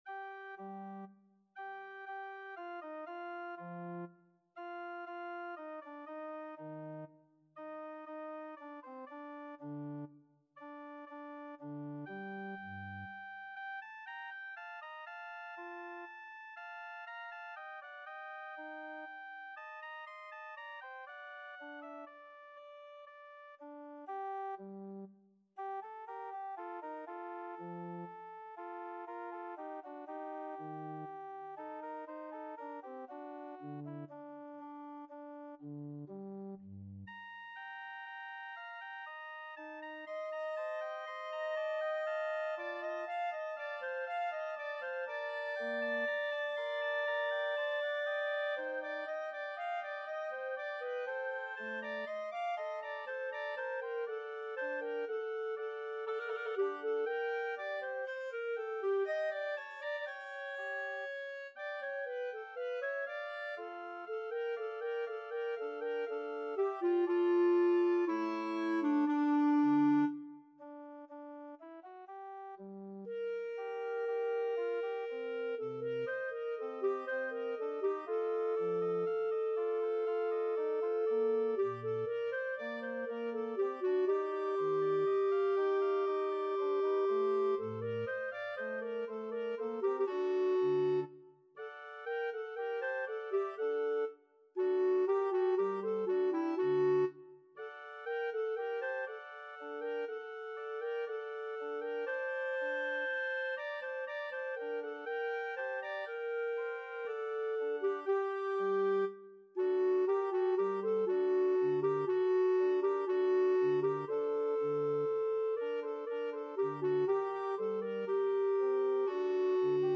Clarinet
G minor (Sounding Pitch) A minor (Clarinet in Bb) (View more G minor Music for Clarinet )
D5-C7
3/4 (View more 3/4 Music)
Adagio
Classical (View more Classical Clarinet Music)